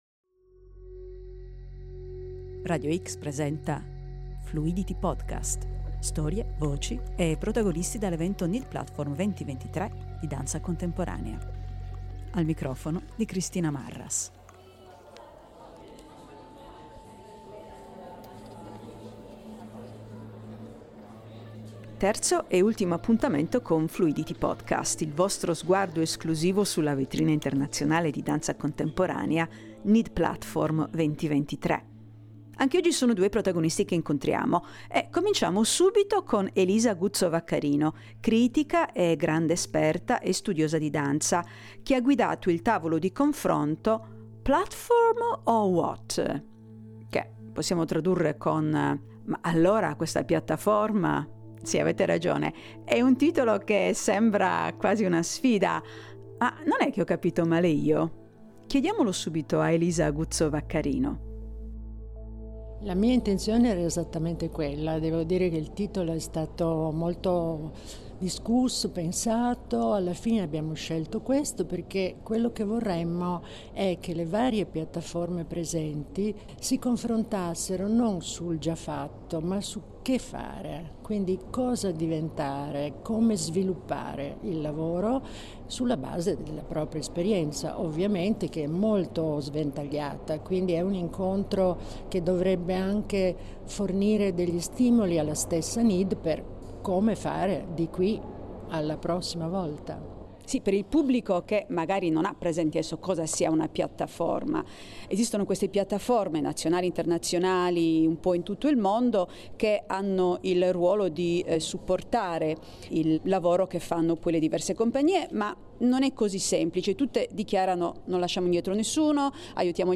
Fluidity podcast #3 – Storie, voci e protagonisti in diretta dal NID Platform 2023